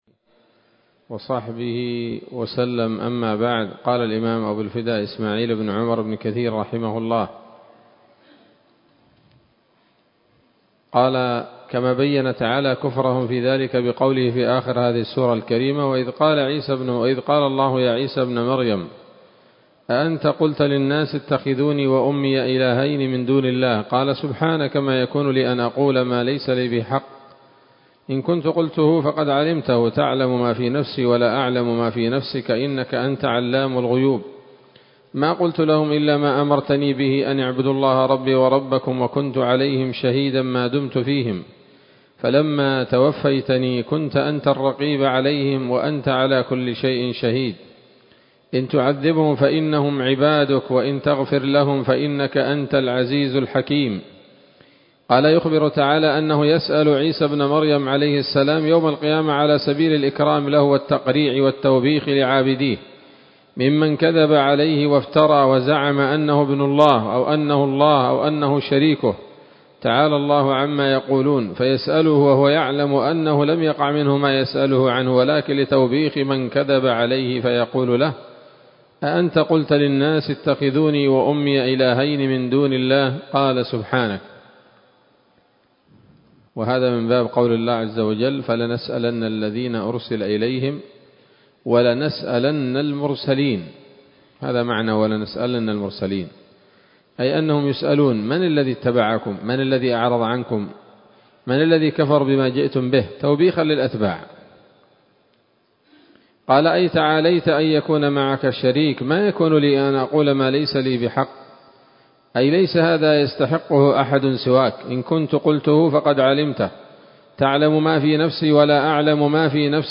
‌‌الدرس الخامس والأربعون بعد المائة من قصص الأنبياء لابن كثير رحمه الله تعالى